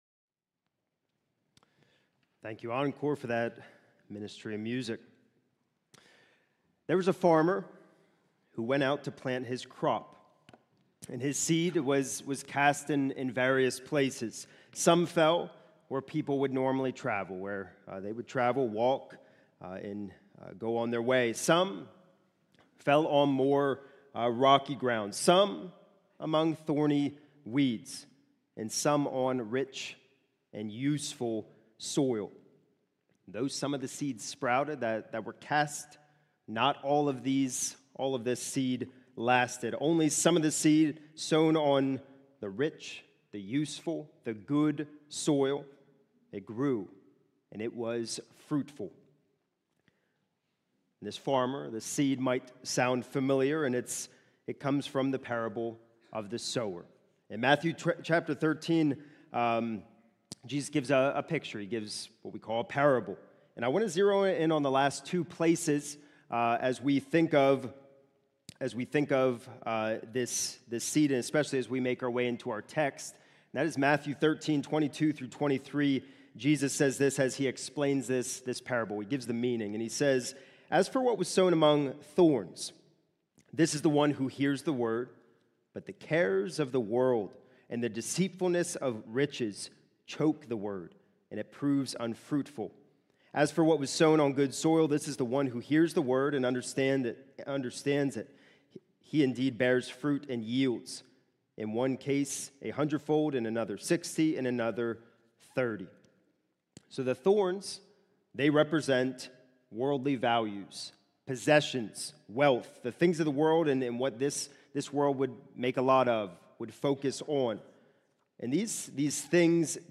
It is taken from 2 Corinthians 5:16-6:2. This sermon includes references to: